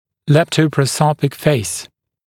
[ˌleptəuprə’səupik feɪs] [-‘sɔp-][ˌлэптоупрэ’соупик фэйс] [-‘соп-]лептопрозопное лицо, длинное лицо